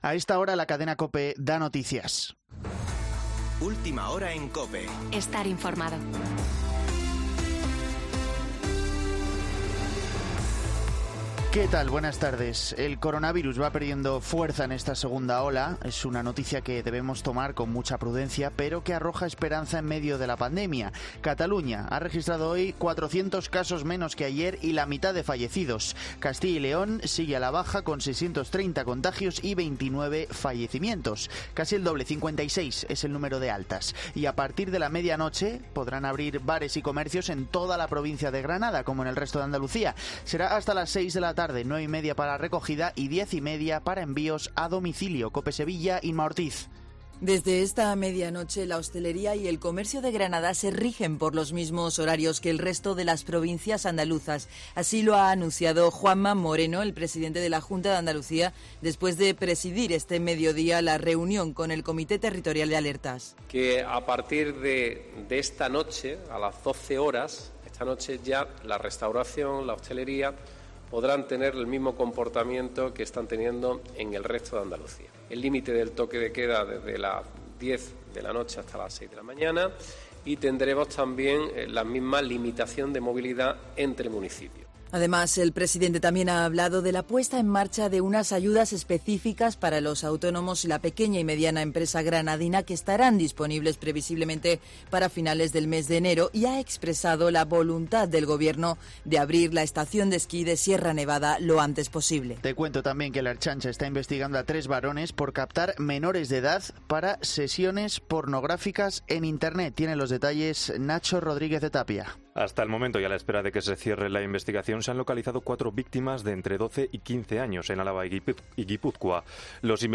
Boletín de noticias COPE del 29 de noviembre de 2020 a las 18.00 horas